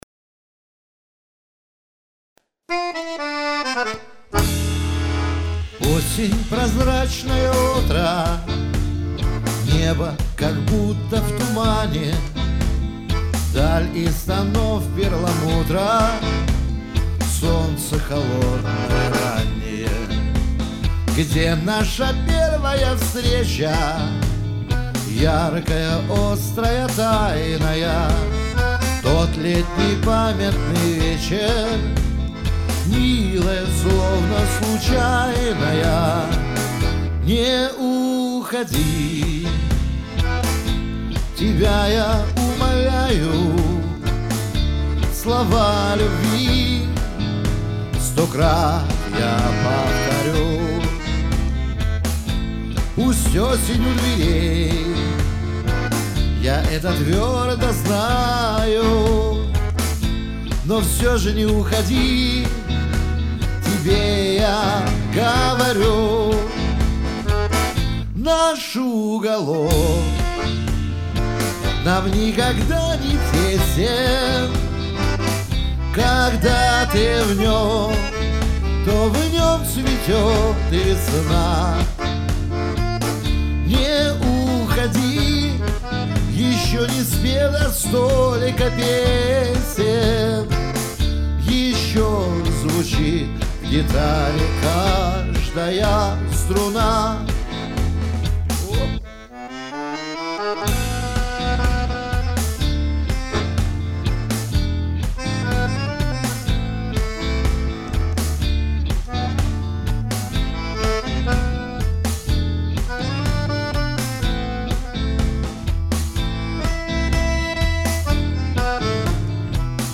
баянист
барабанщик